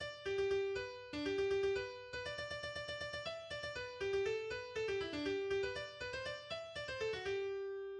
chanson de cowboys